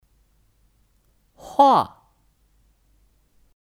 画 Huà: